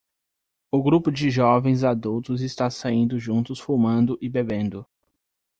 Pronounced as (IPA)
/saˈĩ.du/